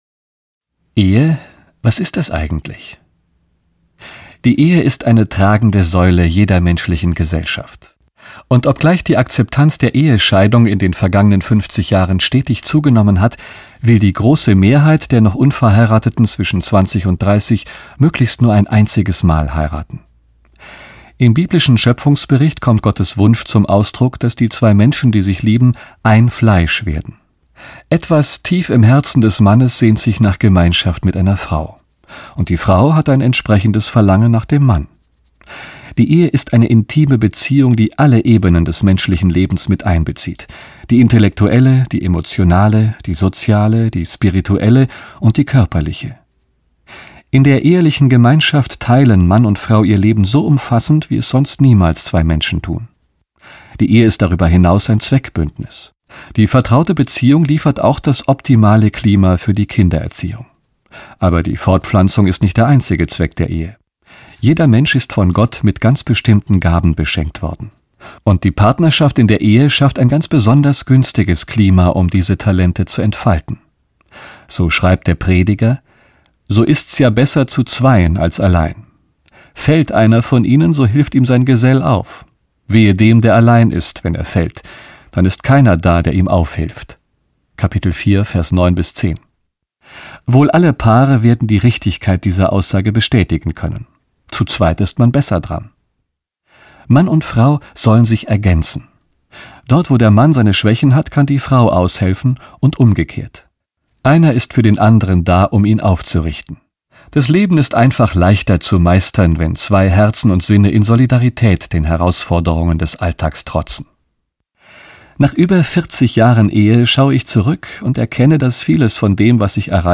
Die vier Jahreszeiten der Liebe - Gary Chapman - Hörbuch